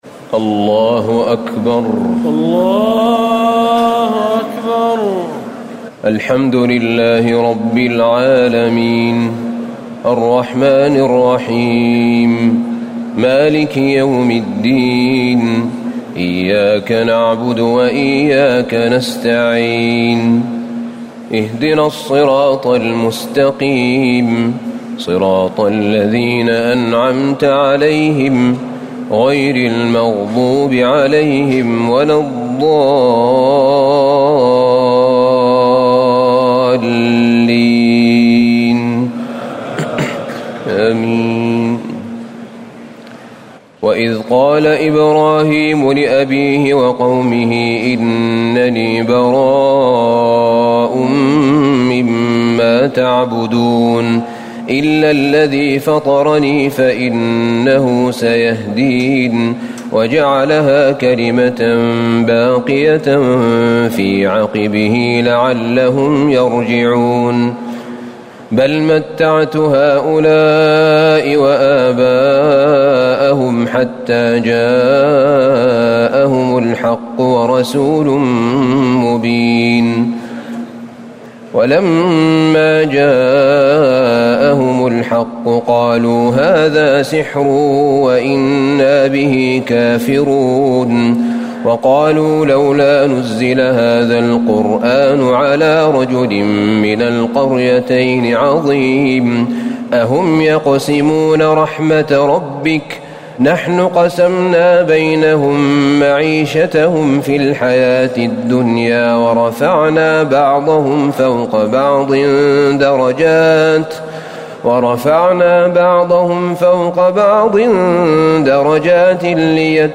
تراويح ليلة 24 رمضان 1439هـ من سور الزخرف (26-89) والدخان و الجاثية Taraweeh 24 st night Ramadan 1439H from Surah Az-Zukhruf and Ad-Dukhaan and Al-Jaathiya > تراويح الحرم النبوي عام 1439 🕌 > التراويح - تلاوات الحرمين